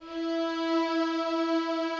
Added more instrument wavs
strings_052.wav